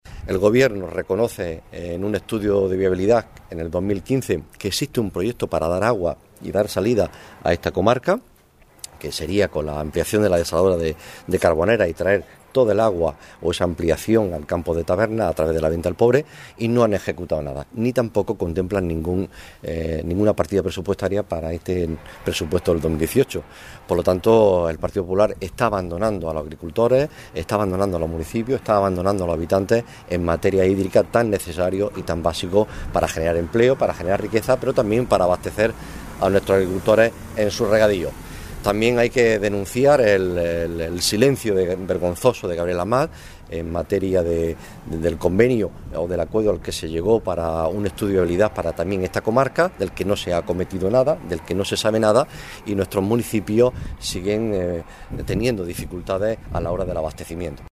Rueda de prensa que ha ofrecido el PSOE de Almería para analizar la inversión de los PGE 2018 en la comarca del Campo de Tabernas